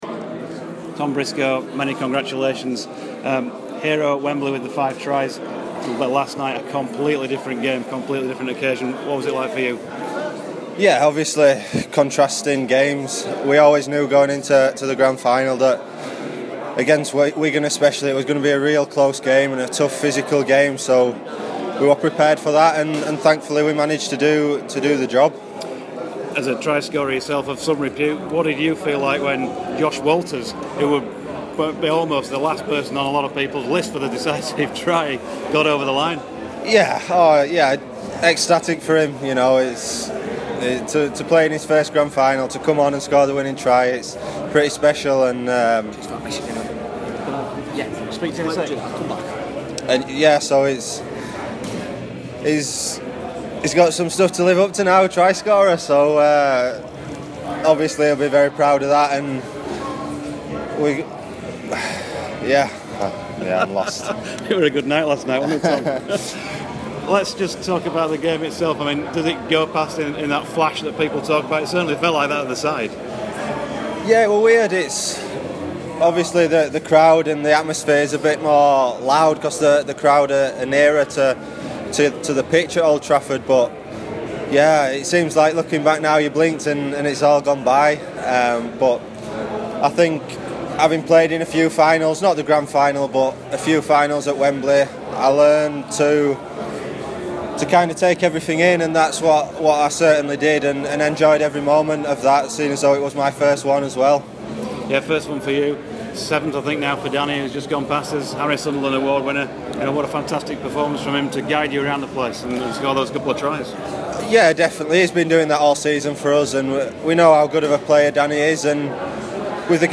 at Leeds Rhino's Grand final celebrations.